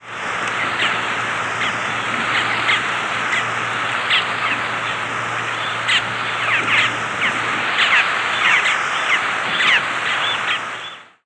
Boat-tailed Grackle diurnal flight calls
Small flock giving "pierh" calls.